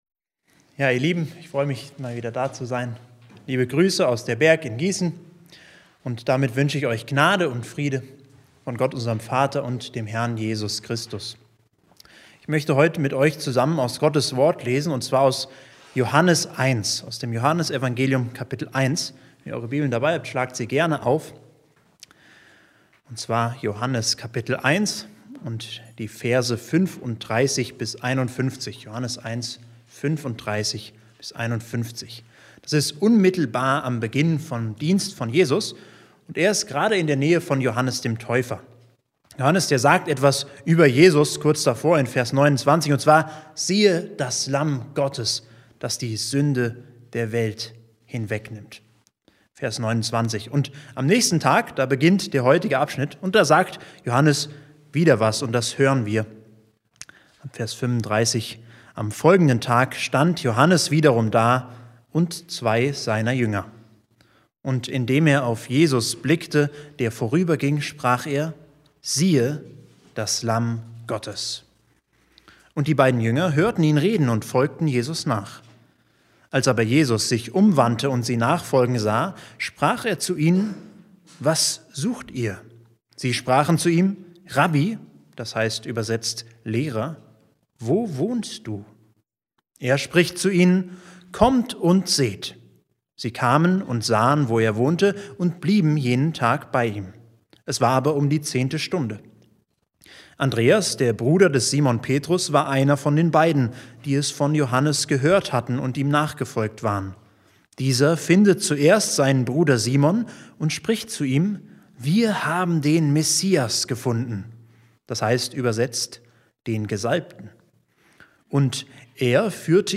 Der Prediger nimmt uns mit in die ersten Begegnungen Jesu im Johannesevangelium.